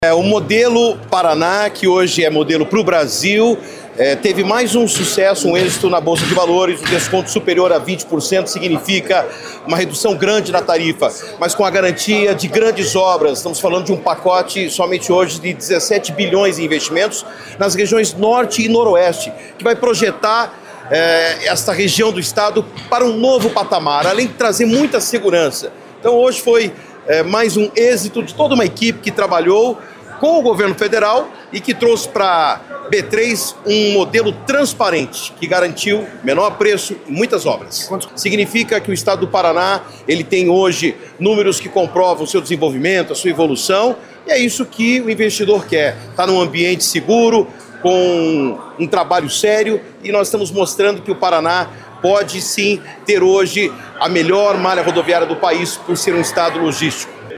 Sonora do secretário de Infraestrutura e Logística, Sandro Alex, sobre o leilão do Lote 4 das concessões rodoviárias do Paraná